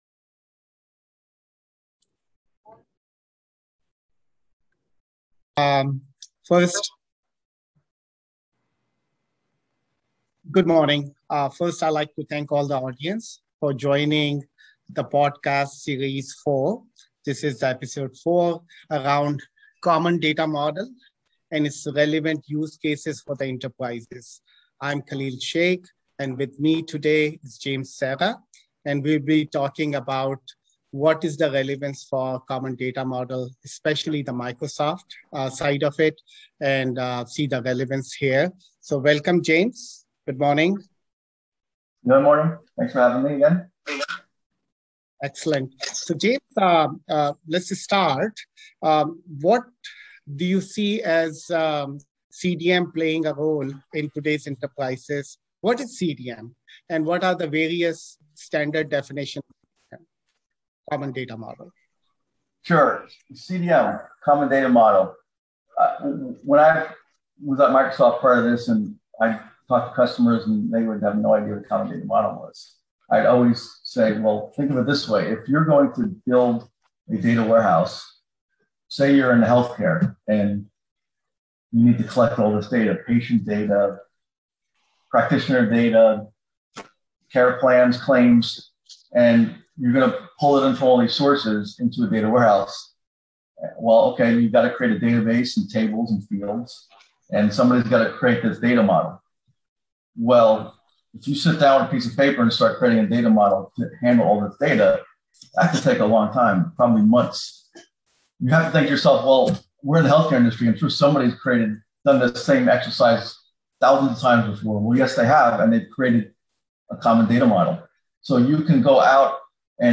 A podcast series where data and analytics leaders discuss enterprise AI, data modernization, and digital transformation strategies.